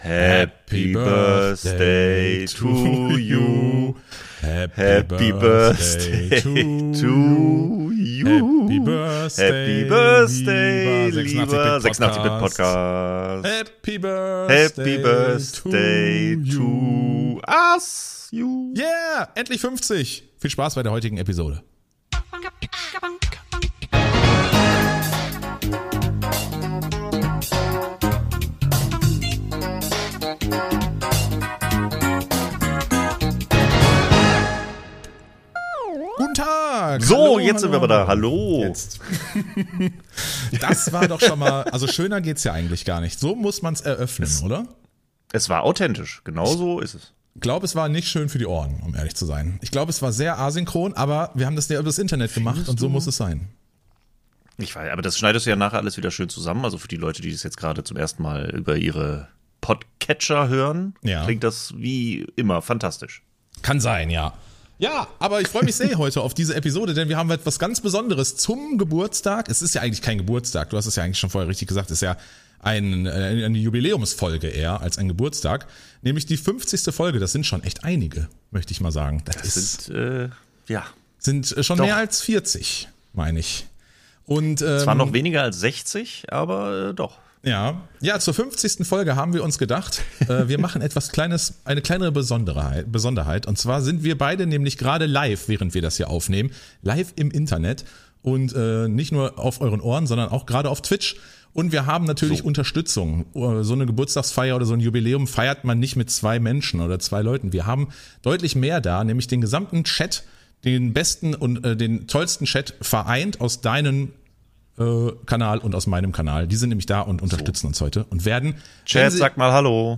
Um diesen Anlass zu feiern haben wir den Podcast live im gemeinsamen Stream aufgenommen.